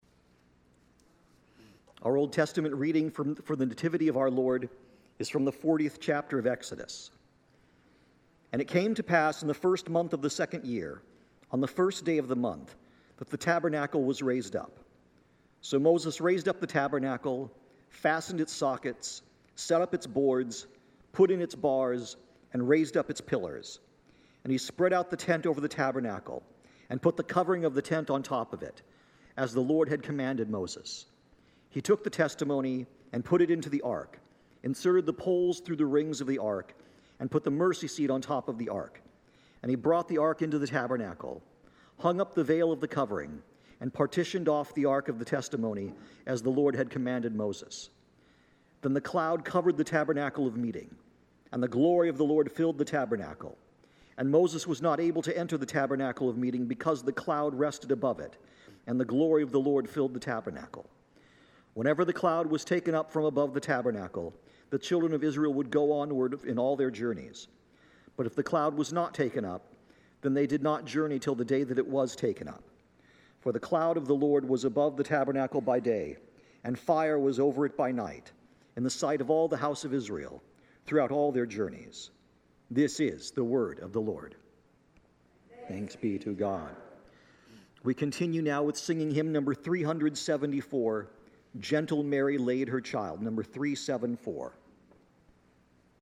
Dec 25, 2025 Xmas Old Testment Reading – Concordia Lutheran Church Findlay